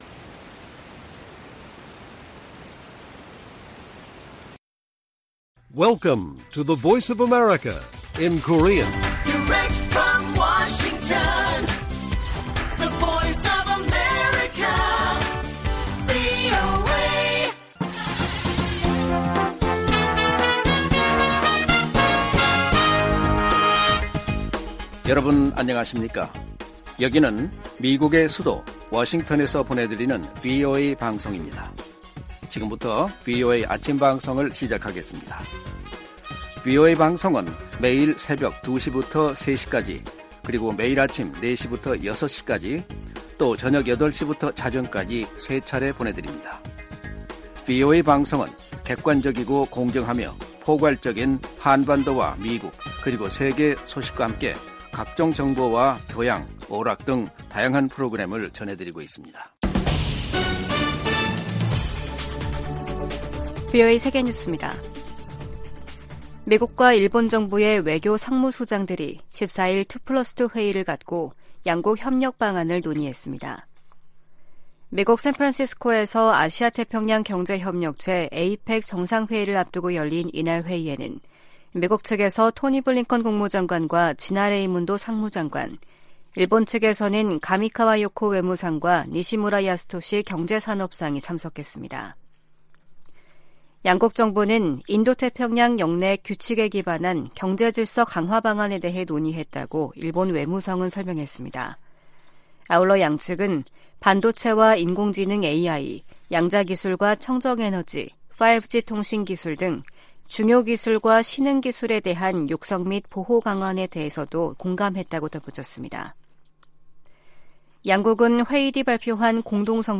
세계 뉴스와 함께 미국의 모든 것을 소개하는 '생방송 여기는 워싱턴입니다', 2023년 11월 16일 아침 방송입니다. '지구촌 오늘'에서는 이스라엘군이 가자시티에 있는 알시파 병원에 진입해 작전을 벌이고 있는 소식 전해드리고, '아메리카 나우'에서는 하원이 내년 1·2월까지 정부를 운영할 임시지출안을 통과시킨 이야기 살펴보겠습니다.